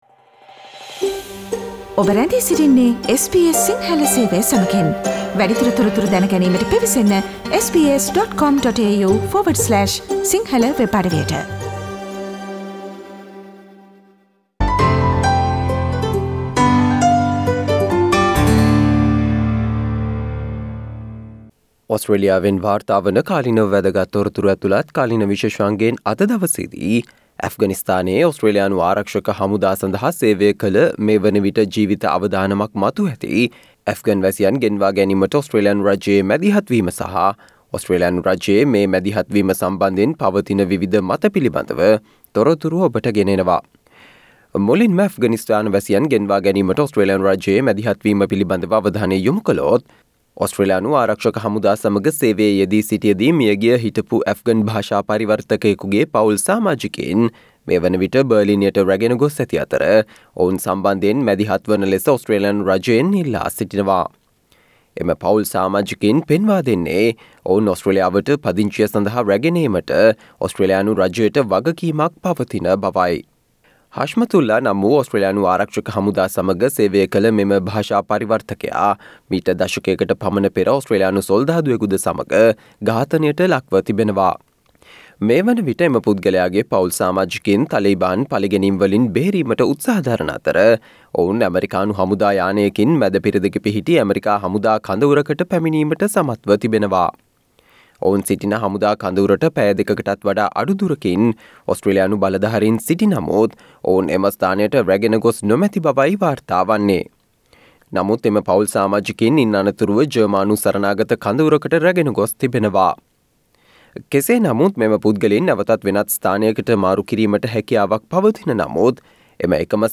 ඇෆ්ගනිස්ථානයේ ඕස්ට්‍රේලියානු ආරක්‍ෂක හමුදා සඳහා සේවය කළ මේ වනවිට ජීවිත අවදානමක් මතුව ඇති ඇෆ්ගන් වැසියන් ගෙන්වා ගැනීමට වන ඕස්ට්‍රේලියානු රජයේ මැදිහත් වීම සහ මෙම මැදිහත්වීම සම්බන්ධයෙන් වන විවිධ මත පිළිබඳව තොරතුරු රැගත් අගෝස්තු 24 වන අඟහරුවාදා ප්‍රචාරය වූ SBS සිංහල සේවයේ කාලීන තොරතුරු විශේෂාංගයට සවන්දෙන්න.